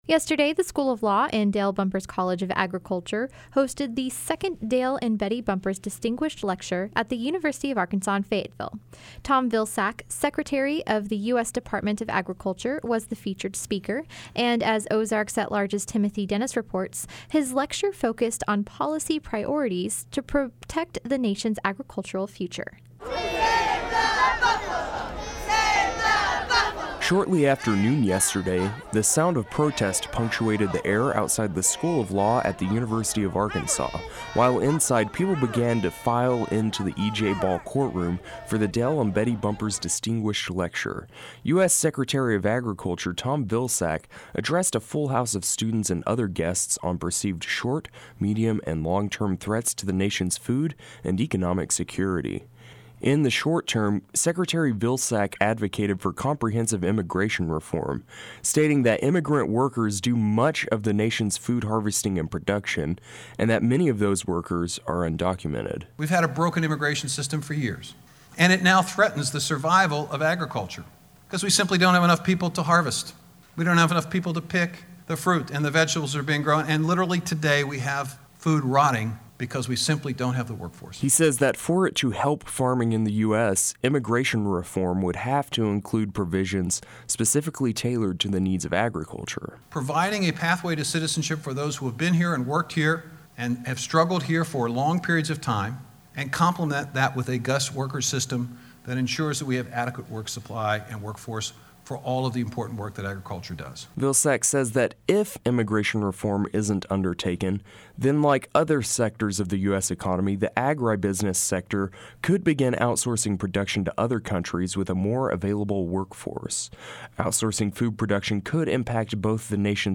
Tom Vilsack, the country's Secretary of Agriculture, was the esteemed speaker of yesterday's Dale and Betty Bumpers Distinguished Lecture at the University of Arkansas. He took the opportunity to speak candidly with the standing room only crowd about short-, medium-, and long-term ag public policy goals, and about opening lines of communication.